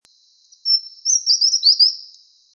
28-3阿里山2011麟胸鷦鷯2.mp3
物種名稱 臺灣鷦眉 Pnoepyga formosana
錄音地點 南投縣 信義鄉 自忠
錄音環境 森林
行為描述 鳴唱